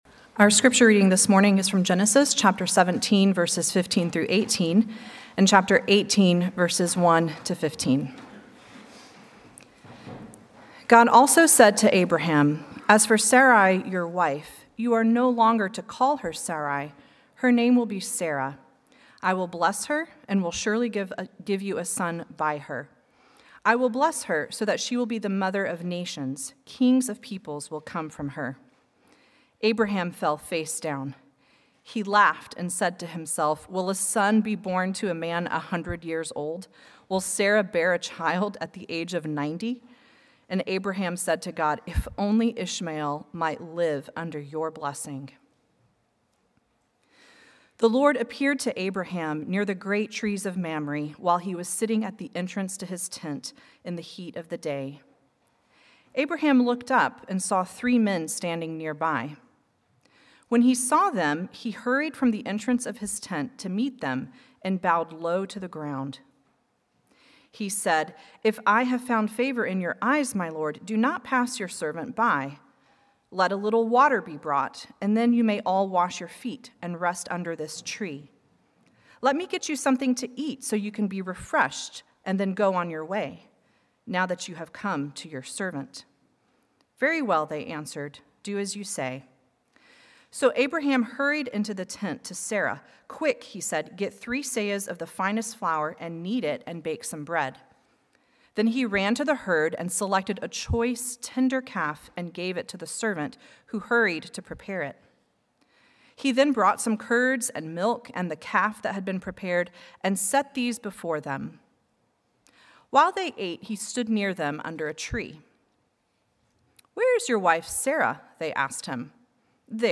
Sermons – North Shore Community Baptist Church